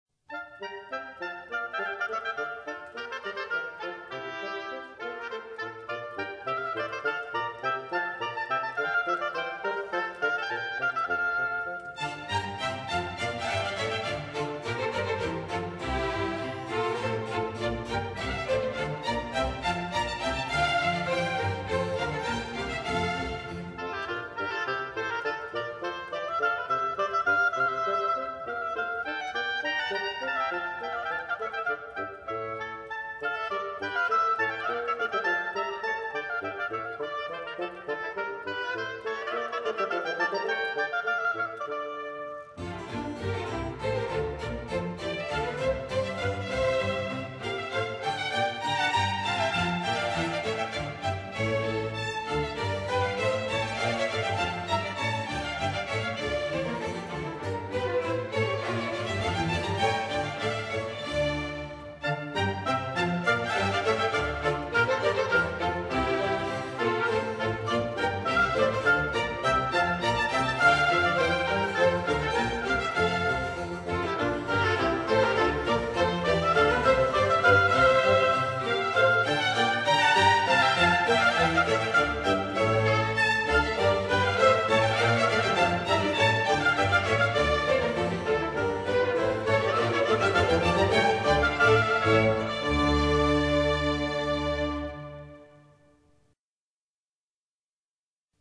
第二部分主题为快板，采用进行曲和舞曲融合的风格。开始带有号角性音调的特点，紧接着是带有附点节奏的的轻快舞曲。